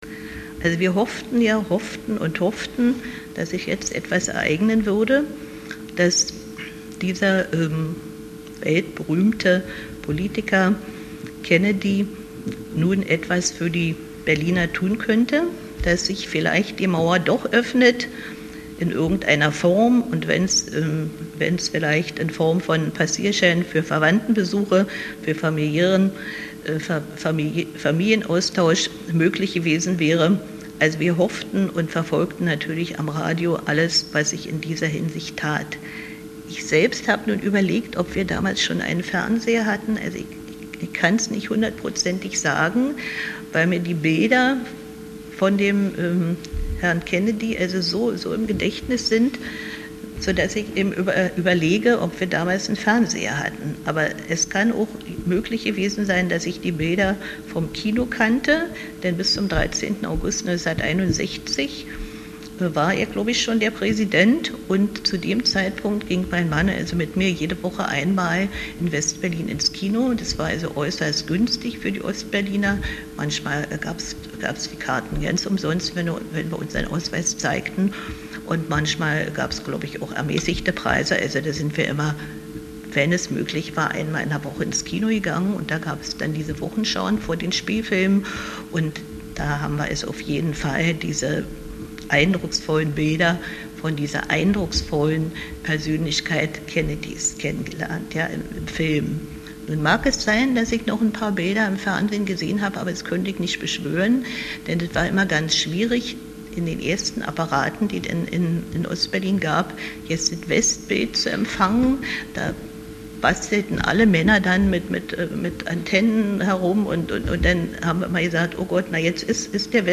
Obwohl beide unzufrieden mit der politischen Führung der DDR waren, zeigten sie in einem Interview auf Fragen zum Kennedy-Besuch sehr unterschiedliche Meinungen.